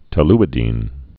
(tə-lĭ-dēn)